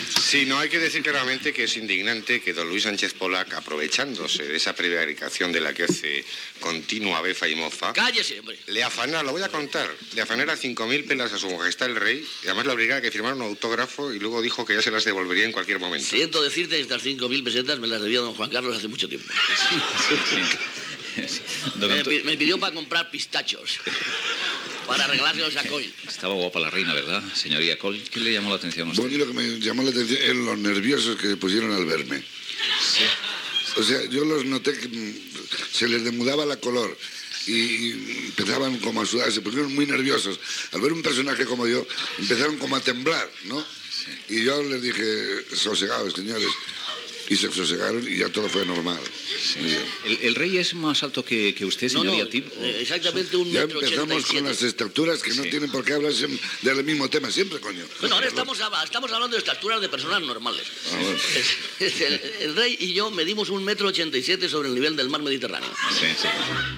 Comentaris amb els humoristes Tip, Coll, Miguel Gila i Forges després del lliurament del títol "Más bonito que un San Luis" al Rei Joan Carles I
Info-entreteniment